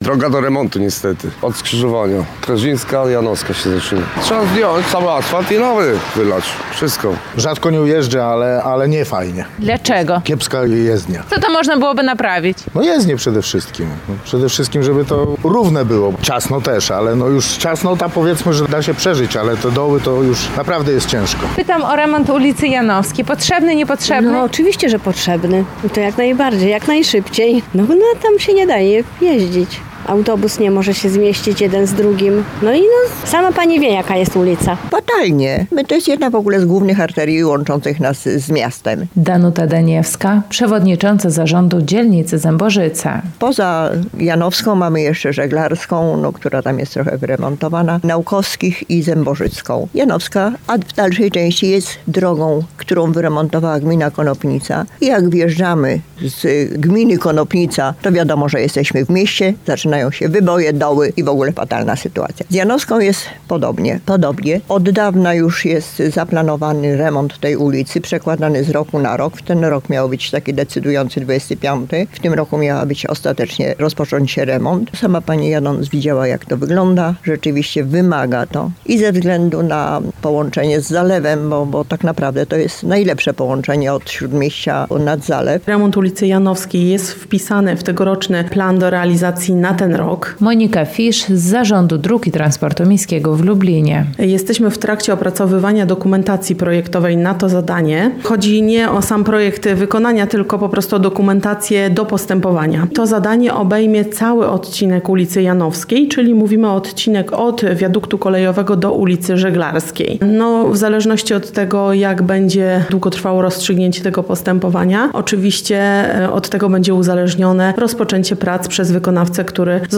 Zapytaliśmy mieszkańców okolicznych ulic jak oceniają stan ul. Janowskiej: